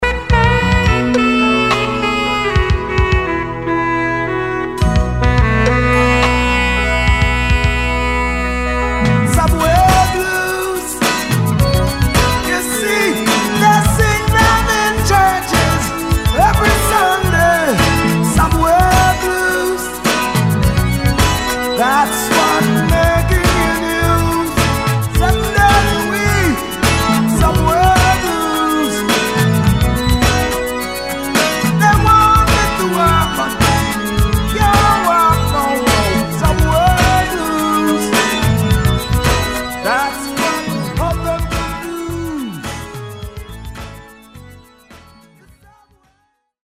With an Eclectic Roots Rock Reggae!